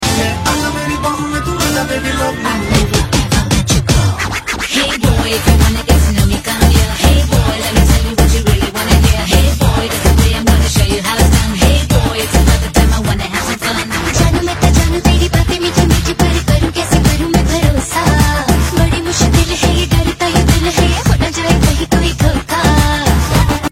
A Bollywood Blast from the Past